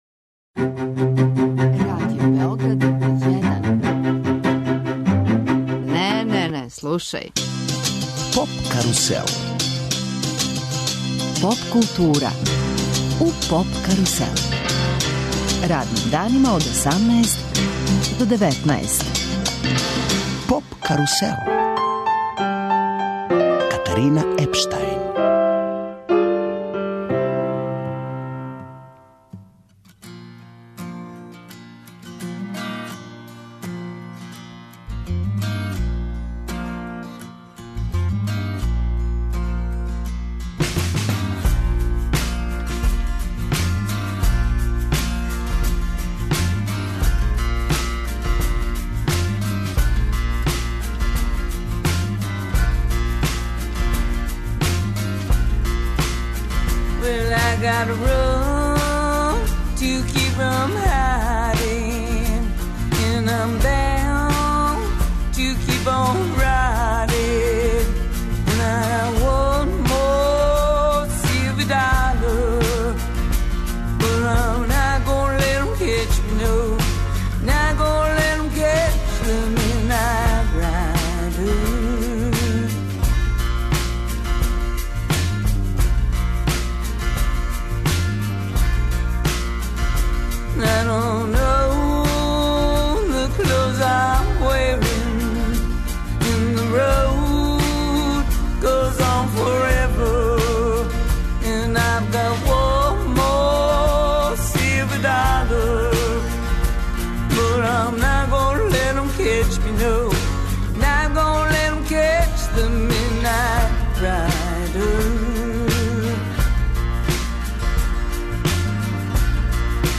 У емисији нам гостује инструментални рок бенд из Тополе 'Погрешна одлука'.
Свих 13 песама су живо снимљене.